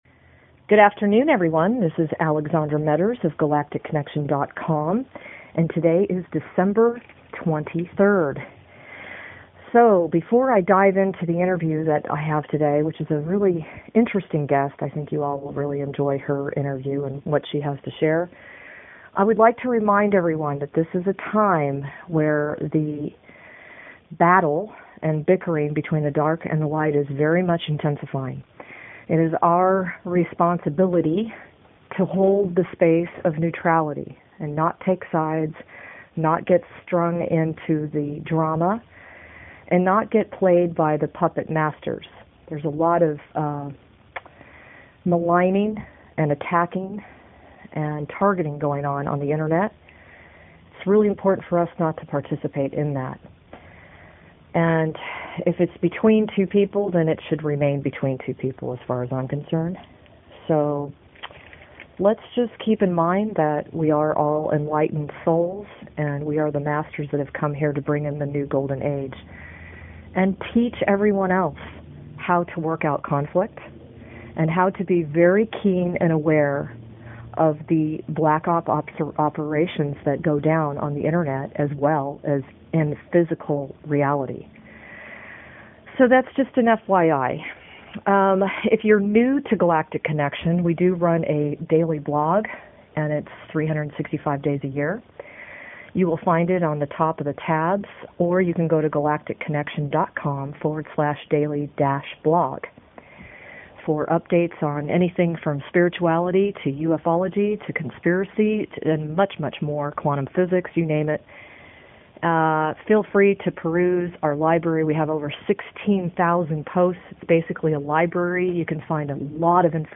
This interview is very timely for ALL Guardians who are ready to step up and out in pursuit of their missions.